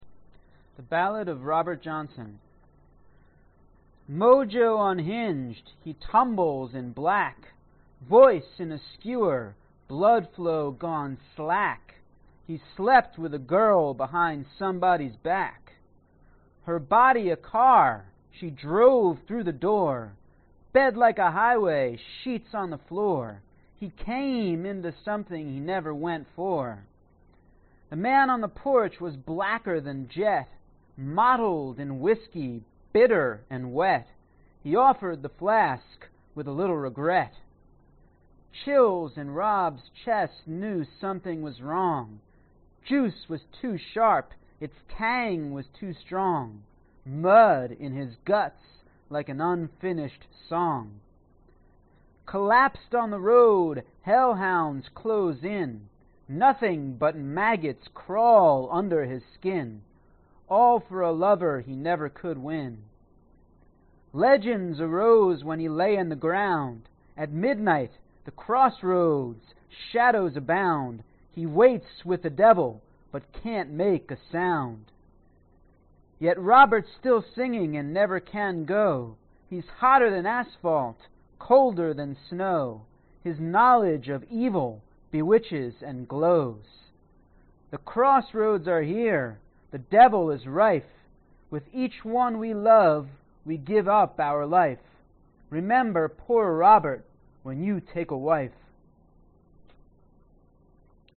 它被收录在《奇闻趣事》手稿中，2011年在费城洛根广场的威斯敏斯特拱门录制。
Tag: 诗歌 口语文件 诗歌文件 当代诗歌 当代民谣 当代口语